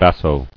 [bas·so]